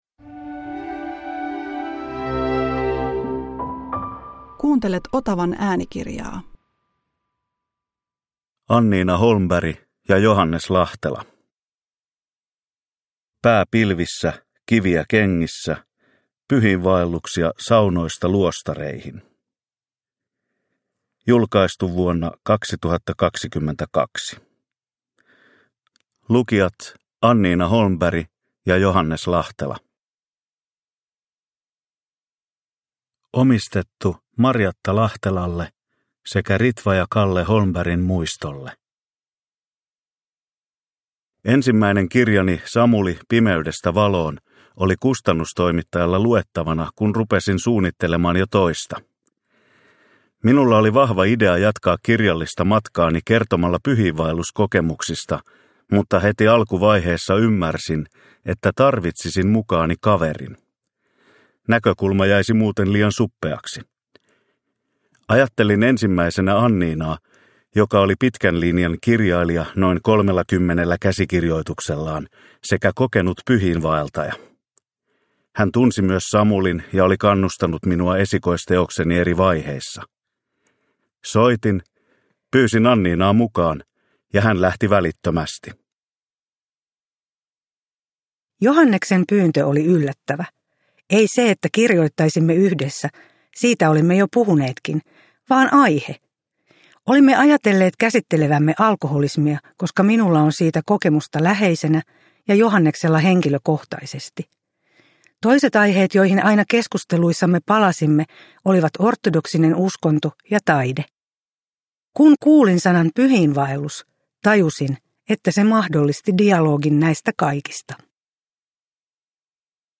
Pää pilvissä, kiviä kengissä – Ljudbok – Laddas ner